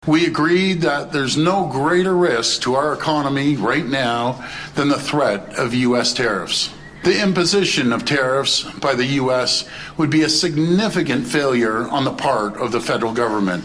While chaos took place in Ottawa on Monday, all 13 of the country’s premier came together for a press conference to show a “Team Canada” approach.
That was Ontario Premier Doug Ford.